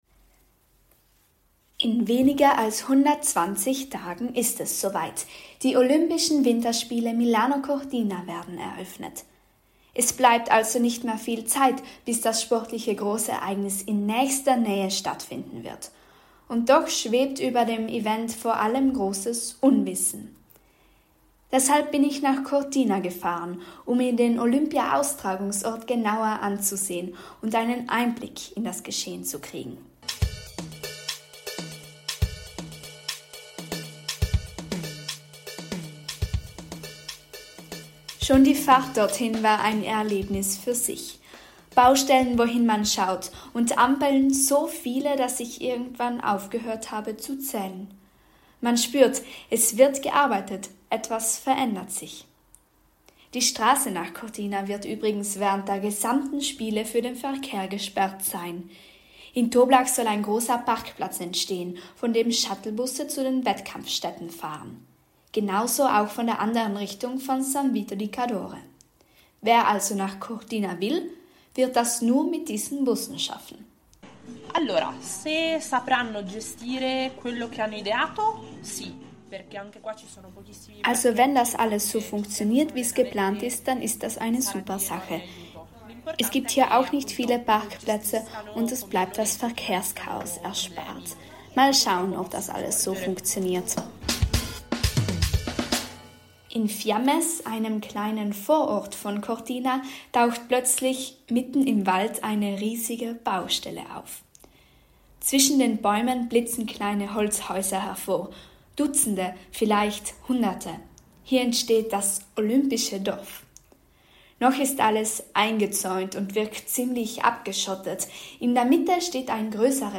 Stimmungsbild aus einer Stadt in den Dolomiten, die bald – zum zweiten Mal nach 1956 – zum Austragungsort olympischer Winterspiele wird.